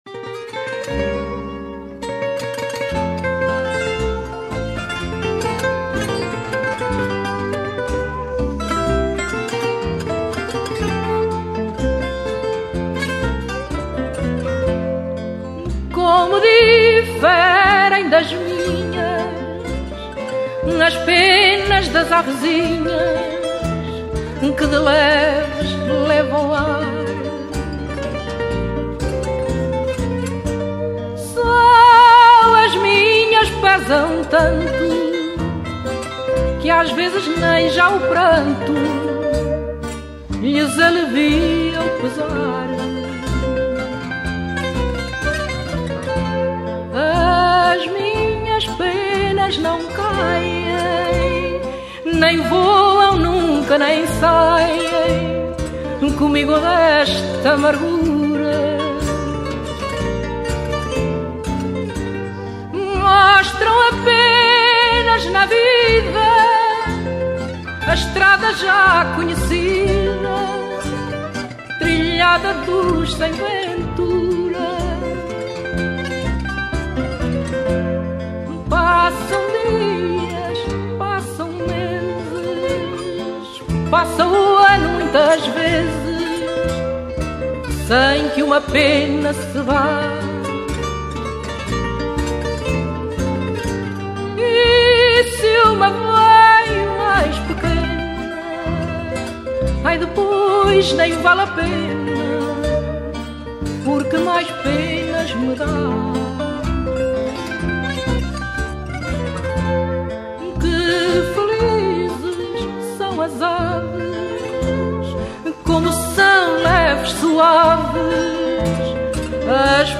chant.
from → Adorable, Fado, Fado castiço / Fado traditionnel